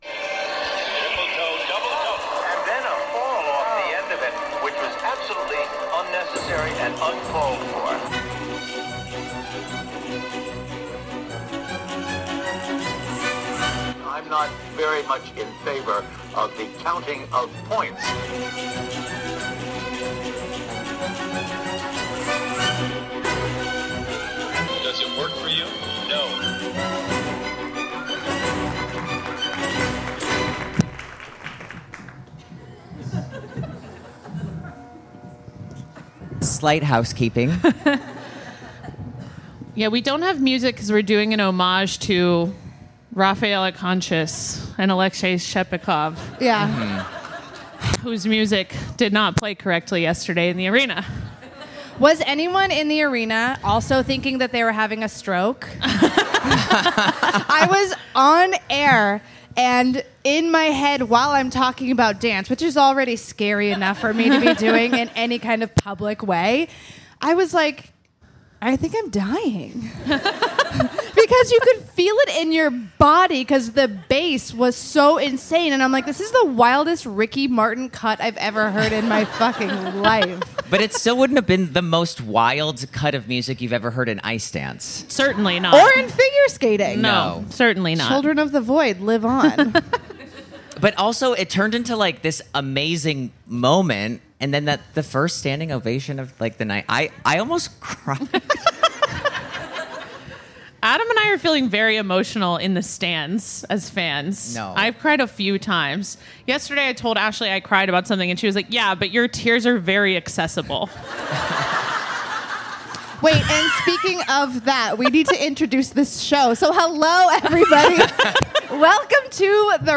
1 Episode 109: Live Show in St. Louis DAY ONE 1:00:46 Play Pause 13h ago 1:00:46 Play Pause Riproduci in seguito Riproduci in seguito Liste Like Like aggiunto 1:00:46 Hello everyone and welcome to your favorite figure skating podcast where we kiss, cry, go live, and have very bad audio for the first 5 minutes of our FIRST live show at the US Championships in St. Louis (Have no fear as the audio dramatically gets better 5 minutes in!!! We never claimed to be audio technicians!!!)